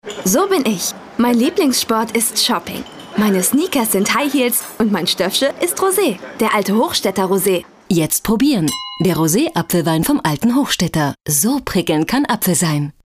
deutsche Profisprecherin. Als Sprecherin von Mädchen bis junge Frau einsetzbar
Sprechprobe: Werbung (Muttersprache):
german female voice over talent.